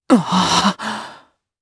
Lucias-Vox_Happy4_jp.wav